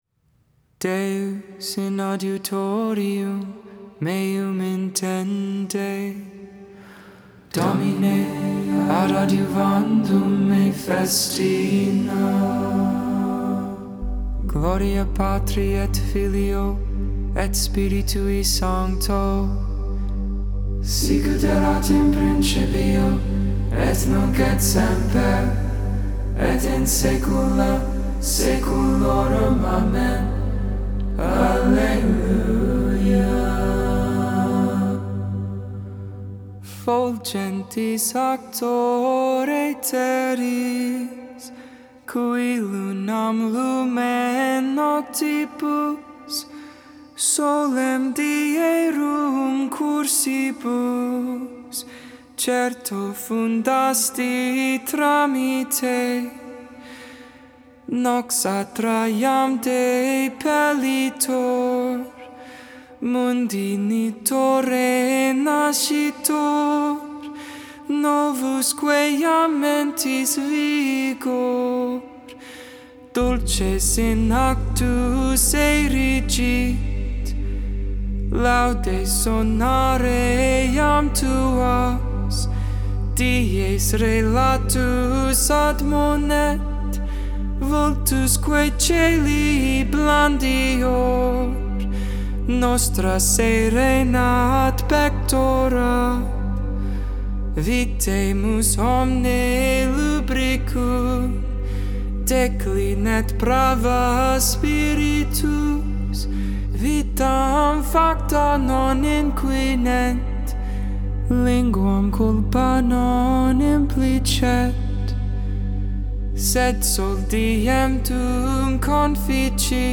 Benedictus (English, Tone 8, Luke 1v68-79) Intercessions: "Remain with us, Lord."